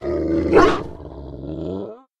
Cri de Dogrino dans Pokémon HOME.